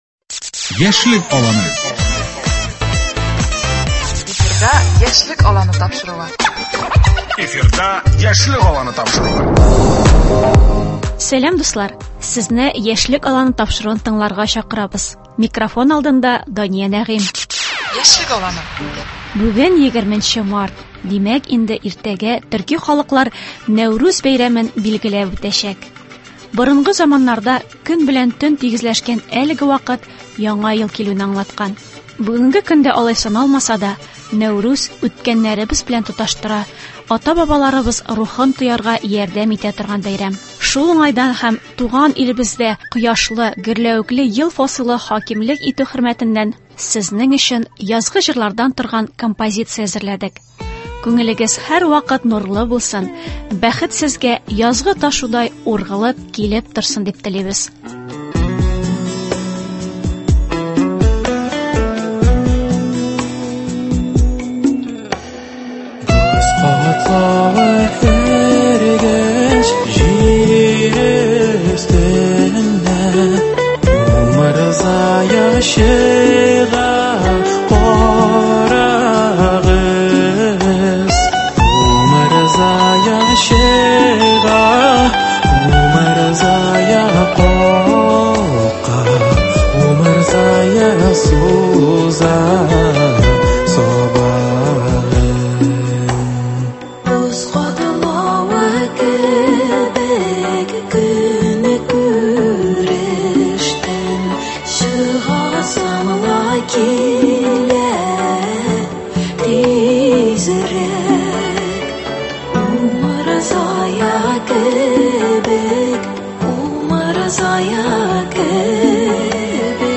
Нәүрүз бәйрәменә багышланган музыкаль сәхифә.